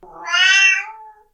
年寄り猫甘え声３
old_cat3.mp3